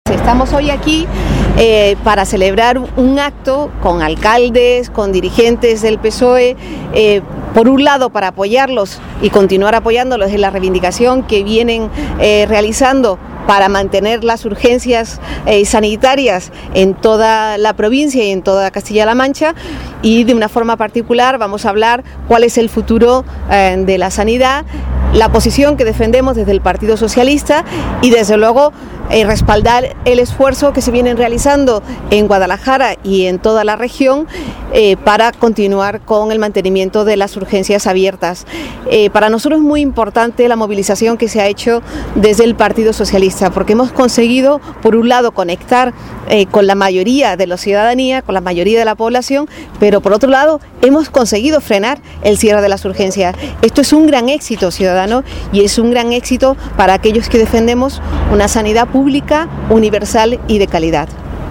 La Secretaria de Política Social ha alabado ante los medios de comunicación “el trabajo y esfuerzo” de estos alcaldes para evitar el cierre de las urgencias sanitarias en las localidades de la zona y se ha mostrado convencida de que la movilización que se ha hecho desde el PSOE, y que ha logrado “conectar con la mayoría de la ciudadanía”, ha conseguido “frenar” el cierre de las urgencias.